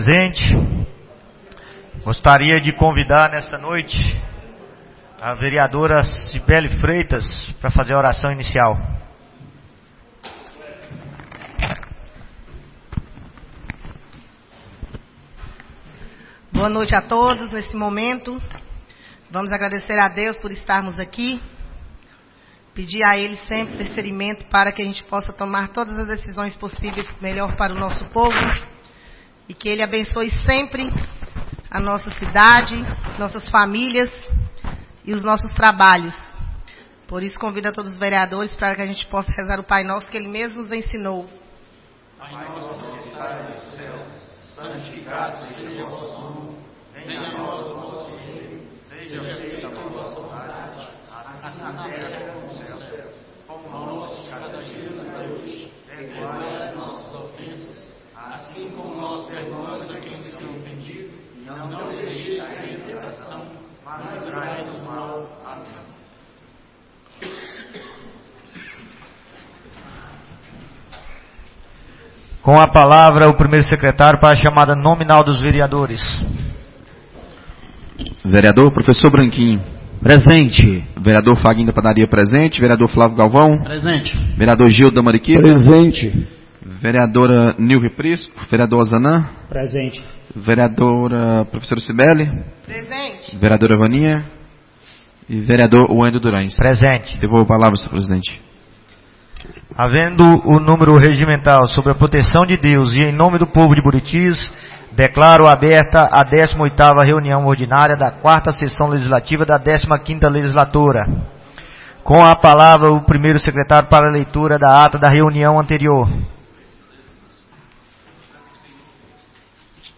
18ª Reunião Ordinária da 4ª Sessão Legislativa da 15ª Legislatura - 03-06-24